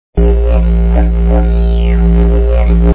didjloop.wav